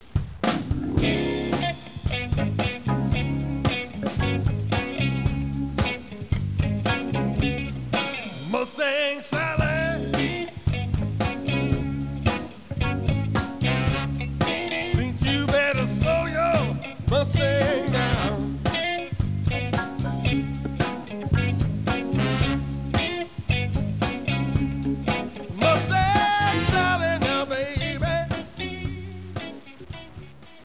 Lead Vocal